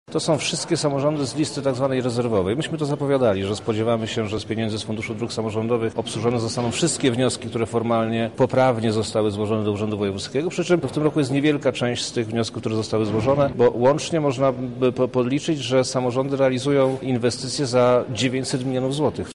Nabór podsumował sam Przemysław Czarnek: